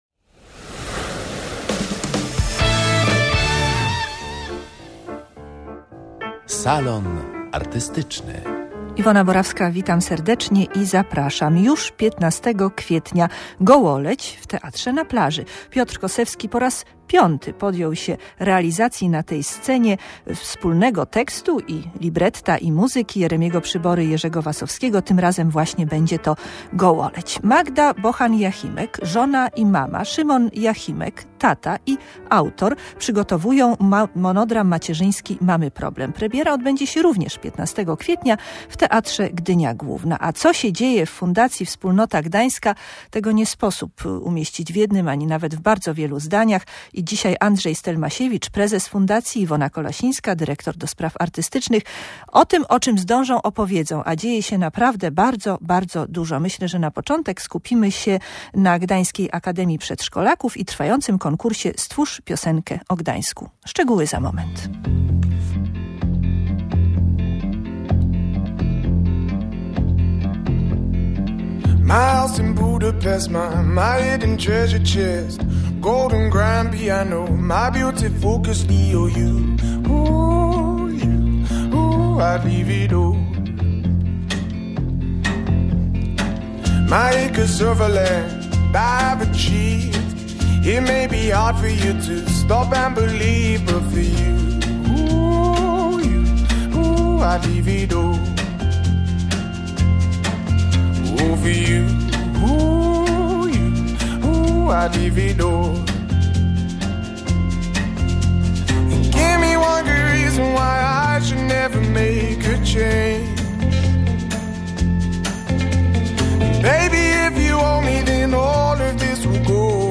W Salonie Artystycznym odwiedzili nas twórcy dwóch teatralnych premier planowanych na 15.04. „Mamy problem.